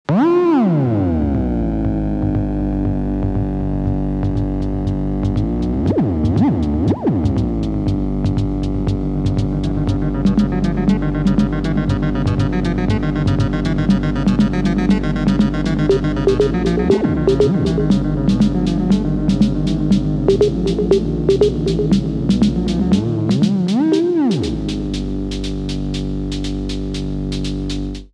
Die Herausforderung für die Start-Stipendiatinnen und Stipendiaten war es, Beethoven´s 5. Synphonie mit dem Modular Synthesizer in Form von Klingeltönen zu zitieren.